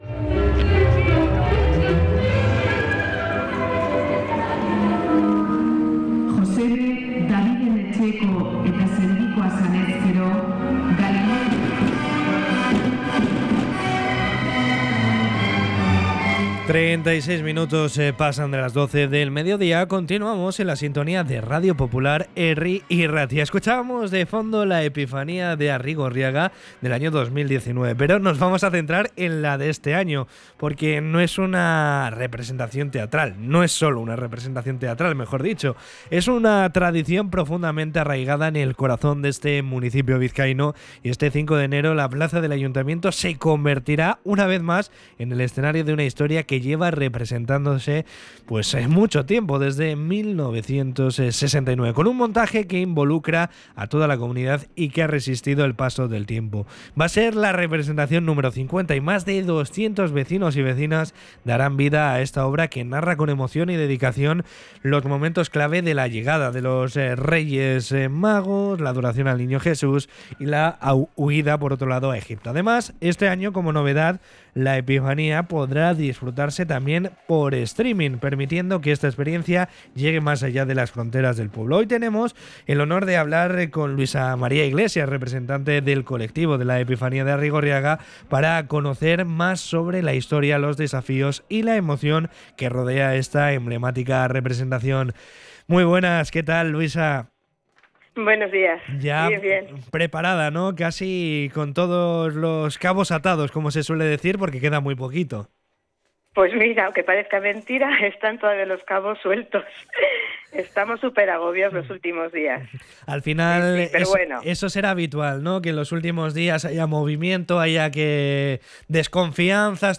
Hablamos con